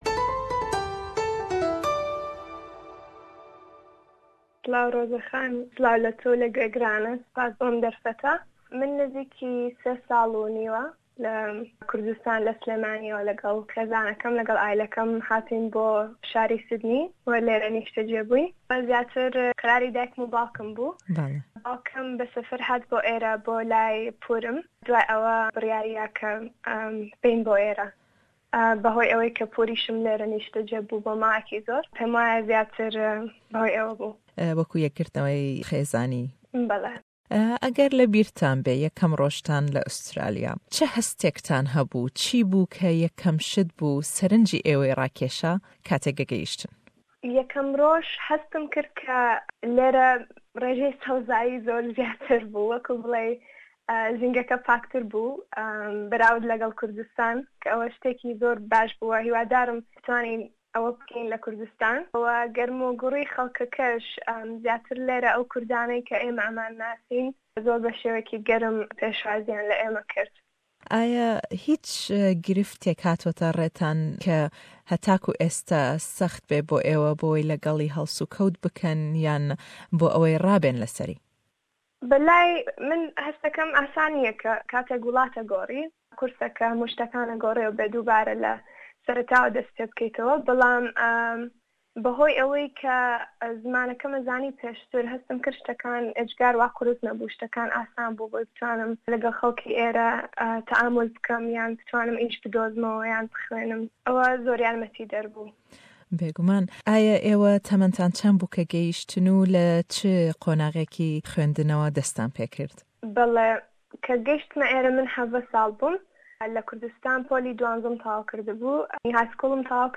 le em hevpeyvîne da ême pirsiyarî xwênd û pirojeyan bo dahatû.